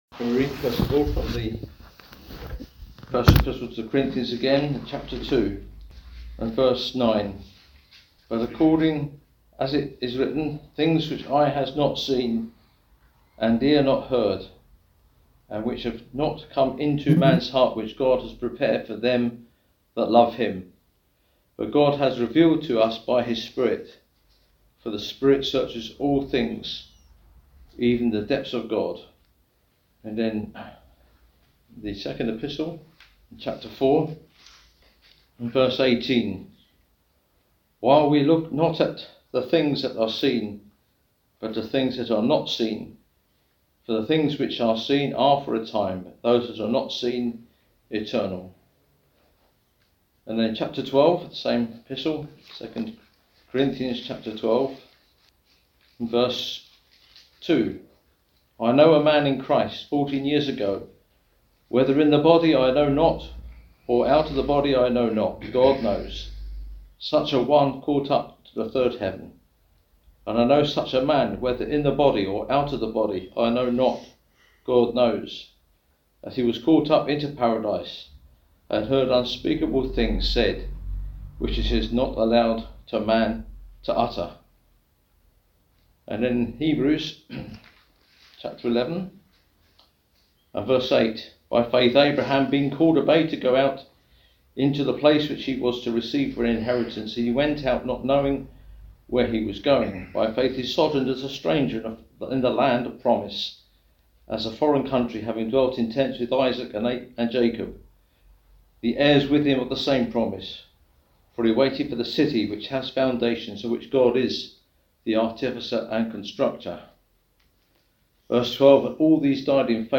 This ministry word explores the eternal realities God has prepared for those who love Him. From the promises Abraham trusted to the wonders revealed by the Spirit, this message calls us to live by faith, seeing beyond the temporary and embracing the invisible things that endure forever.